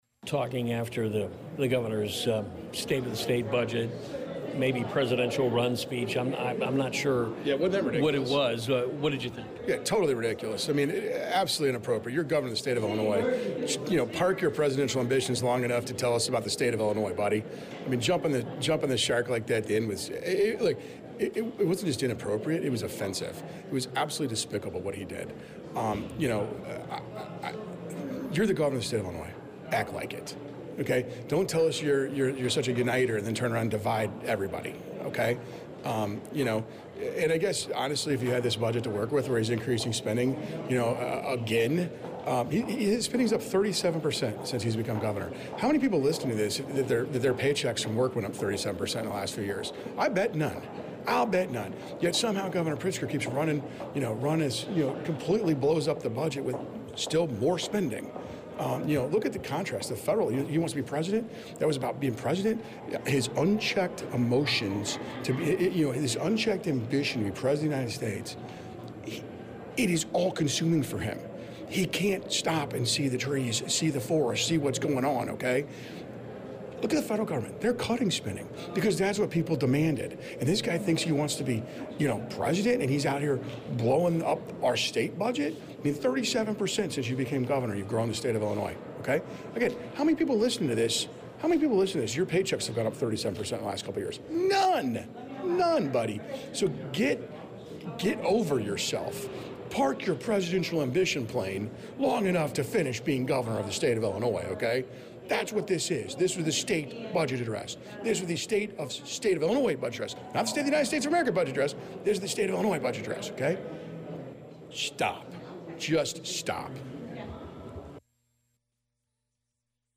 STATE SENATOR ROSE CONVERSATION